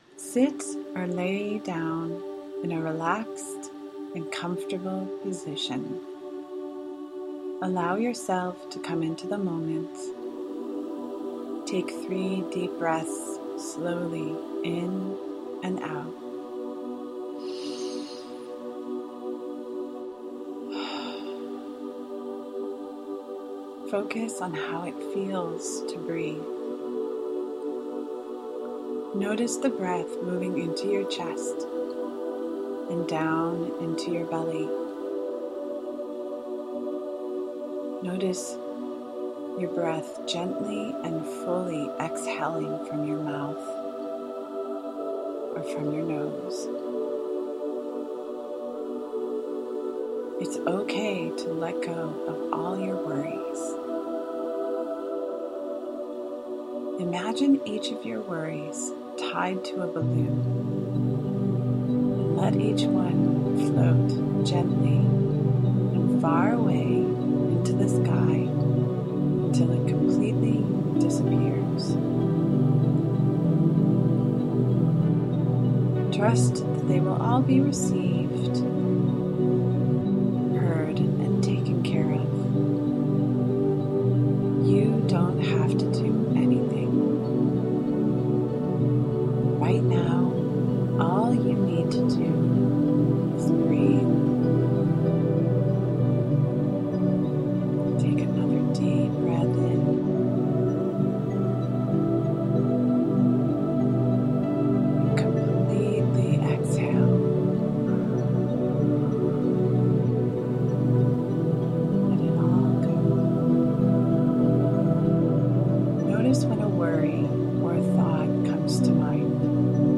Pregnancy Support Guided Meditation
PregnancySupportGuidedMeditation.mp3